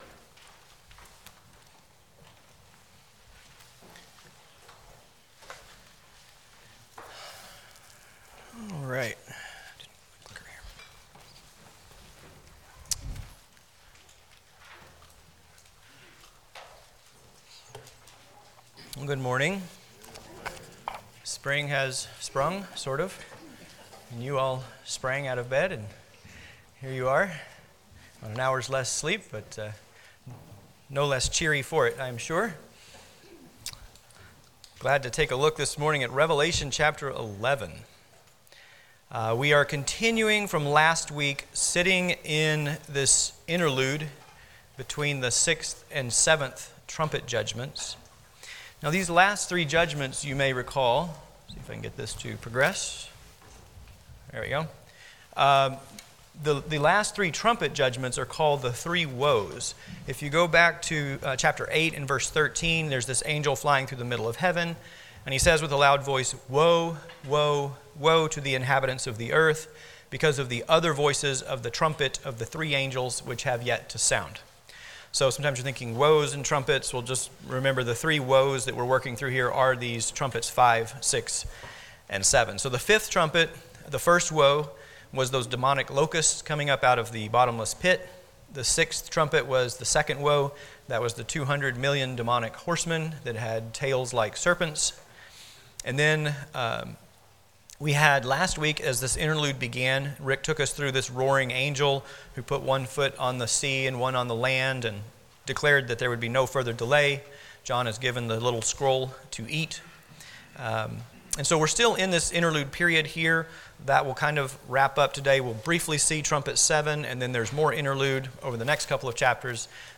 Passage: Revelation 11 Service Type: Sunday School